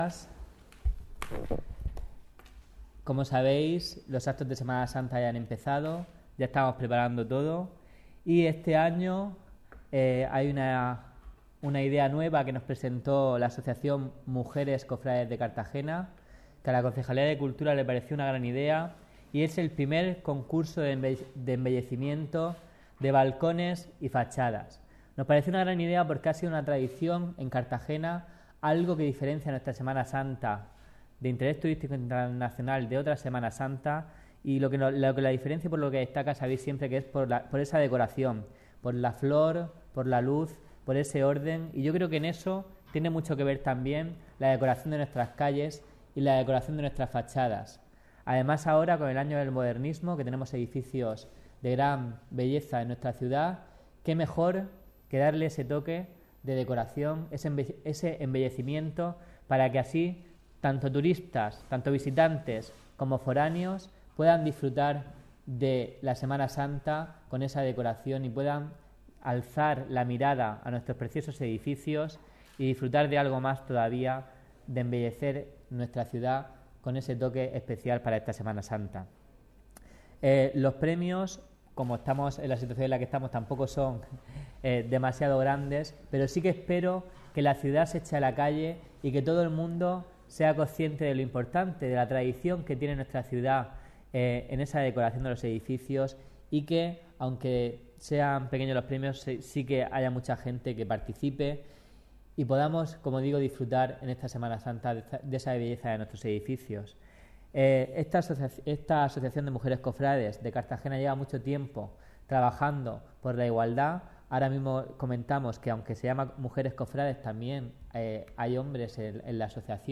Audio: 2016-02-24 Rueda de prensa del concurso de balcones de Semana Santa (MP3 - 14,45 MB)